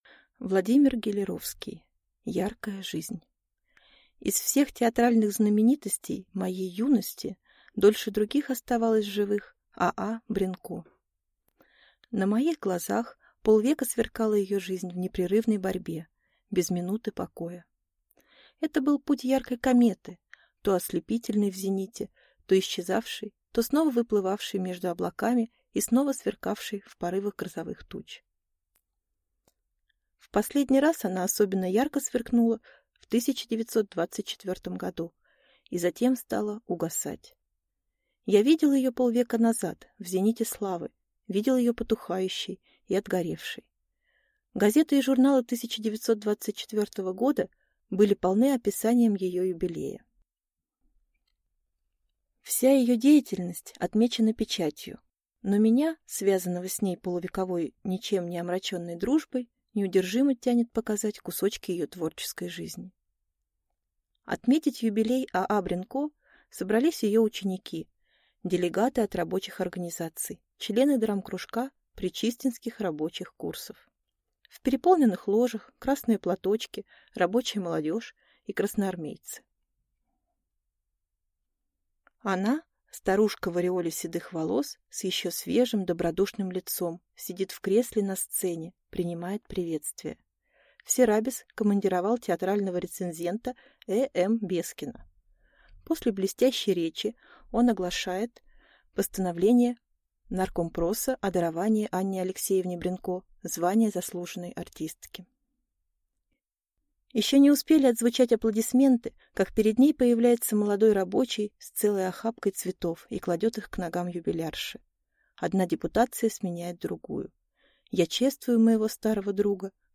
Аудиокнига Яркая жизнь | Библиотека аудиокниг
Прослушать и бесплатно скачать фрагмент аудиокниги